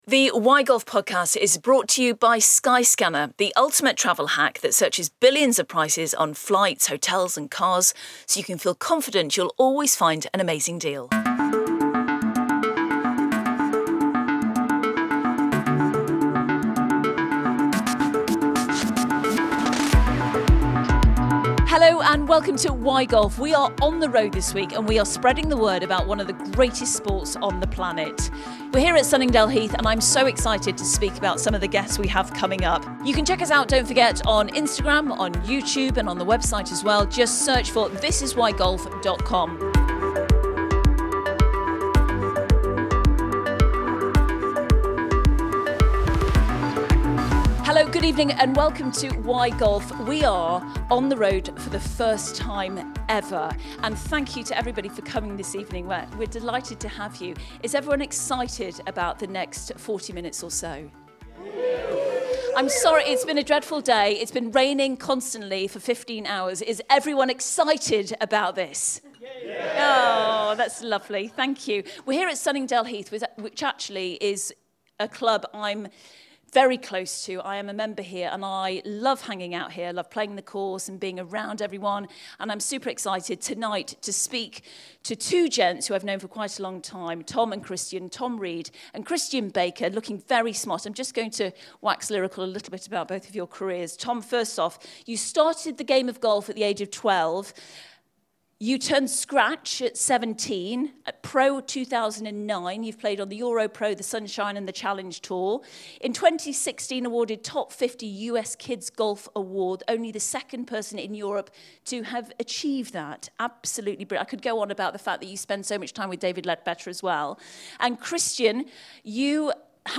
This week Why Golf has travelled to Sunningdale Heath Golf Club to find out why it’s so loved by its members!
Recorded in front of a live audience